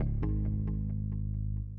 这些样本是使用各种硬件和软件合成器以及外部第三方效果创建的。
声道立体声